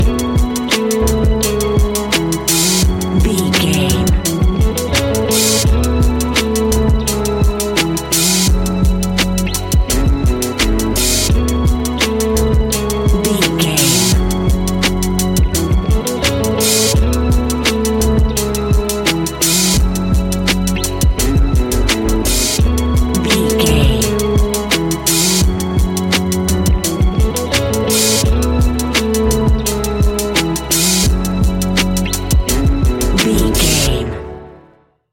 Ionian/Major
F♯
chilled
laid back
Lounge
sparse
new age
chilled electronica
ambient
atmospheric
instrumentals